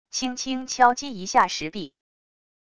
轻轻敲击一下石壁wav音频